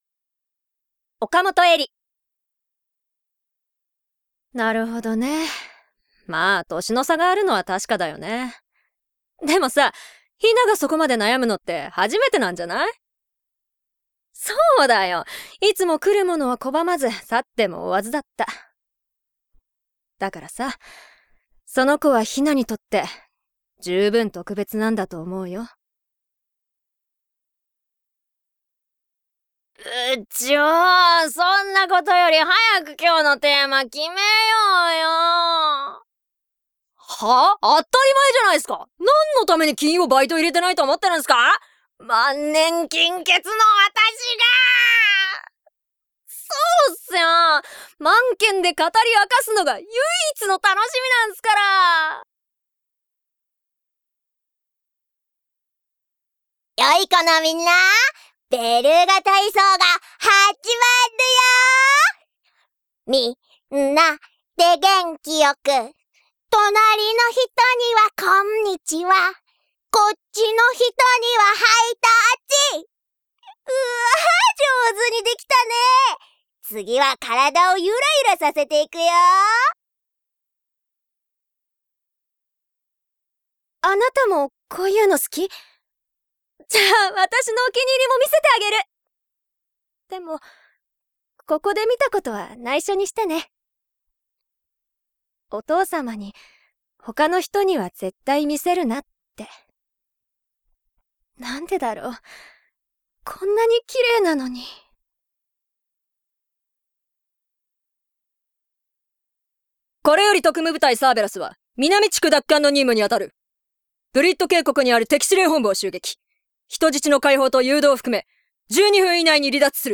方言： 三河弁
VOICE SAMPLE